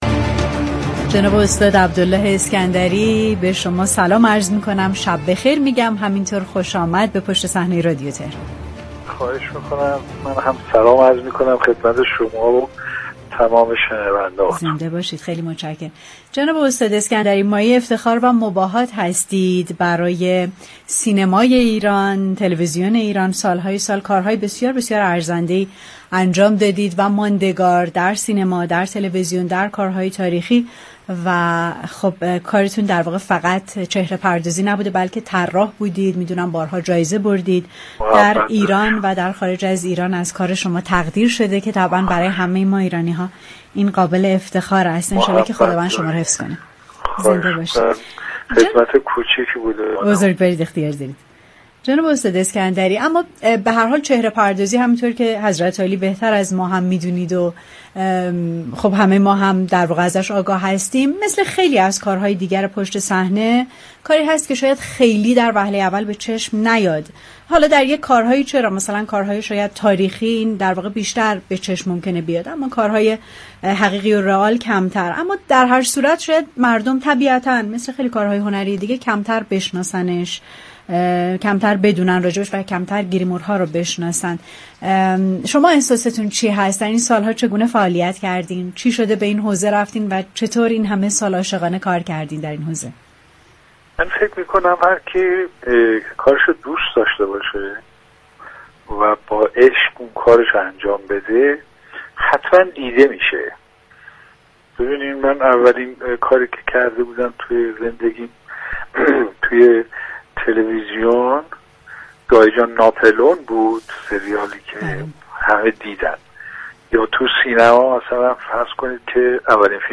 در گفتگو با برنامه پشت صحنه درباره حرفه چهره پردازی